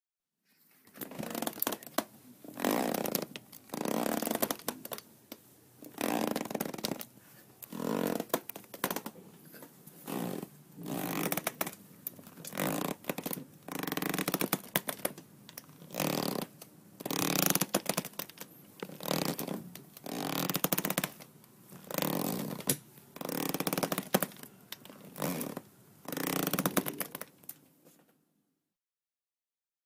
Rope_Cracking.mp3